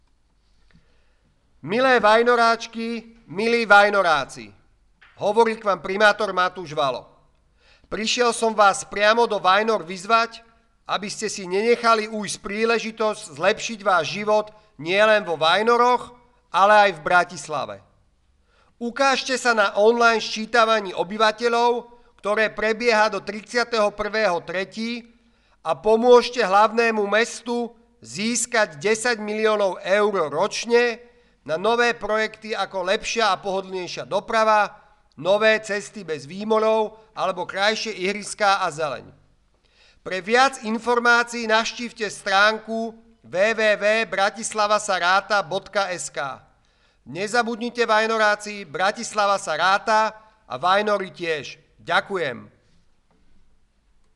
primator_valo_osobne_pozyva_na_scitanie_obyvatelov.mp3